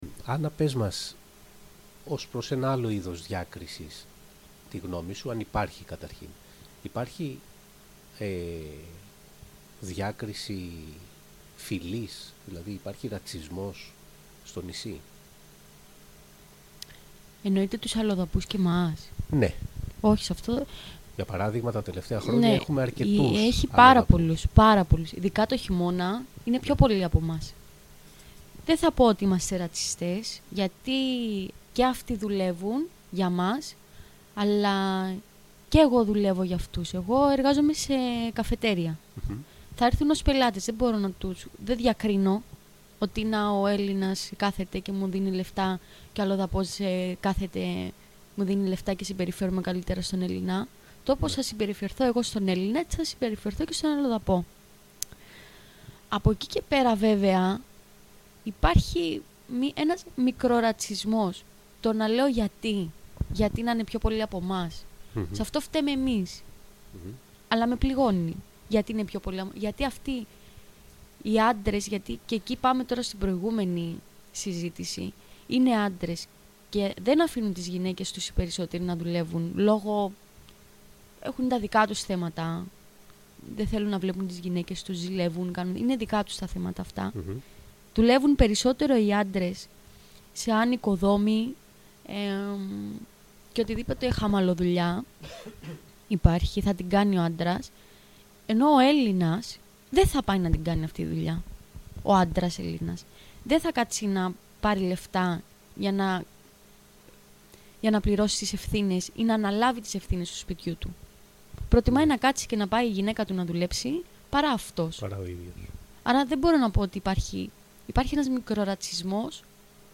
Discriminations between men and women (4th interview)